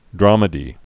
(drämĭ-dē, drămĭ-)